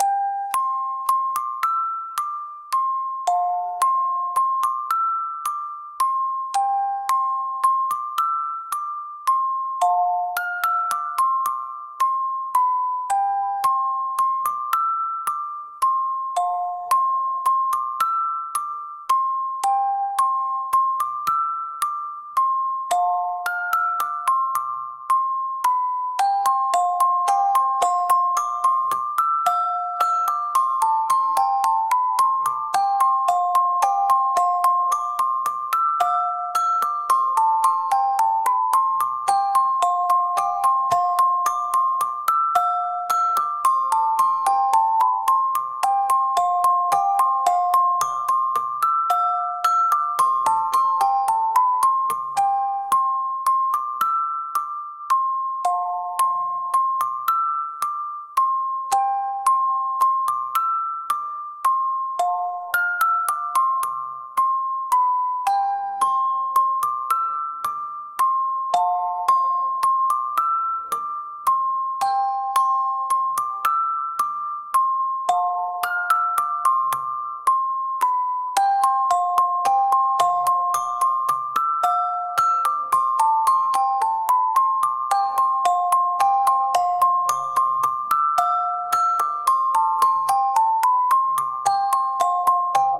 「オルゴール」